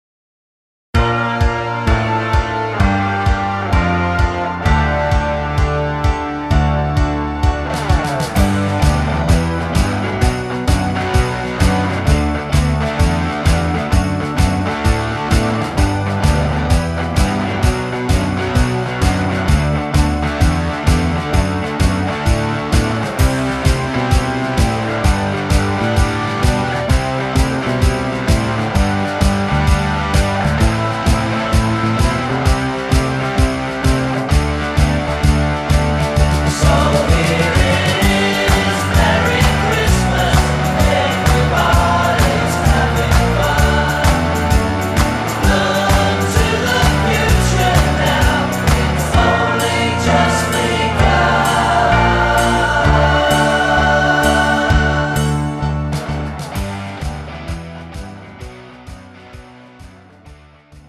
장르 pop 구분